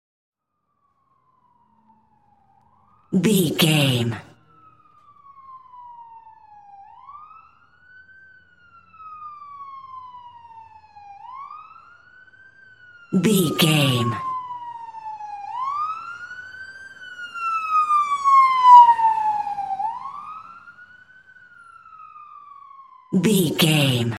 Ambulance Ext Passby Large Siren 80
Sound Effects
urban
chaotic
emergency